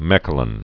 (mĕkə-lən, mĕ-) also Mech·lin (mĕklĭn) or Ma·lines (mə-lēnz, mä-lēn)